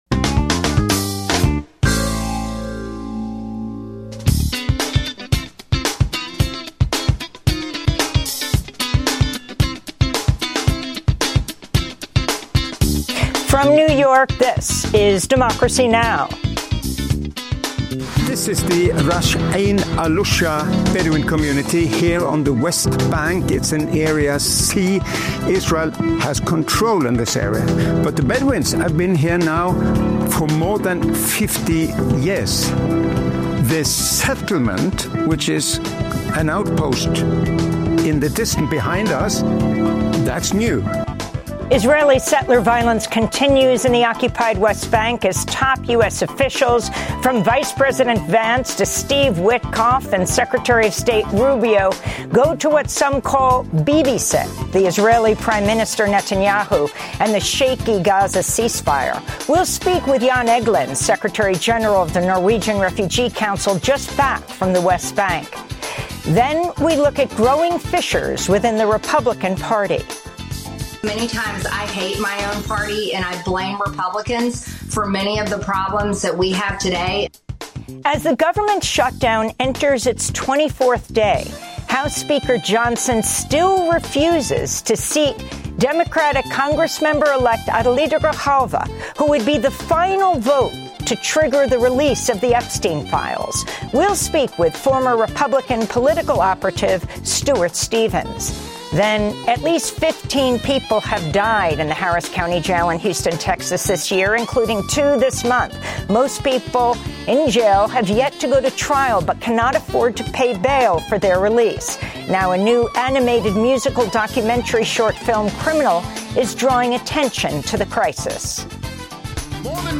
daily, global, independent news hour